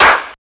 NYC125CLAP-L.wav